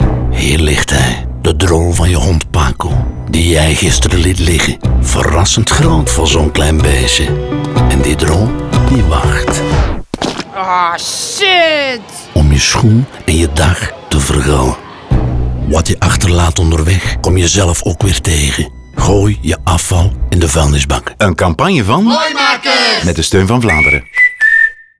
Radiospot Hond Paco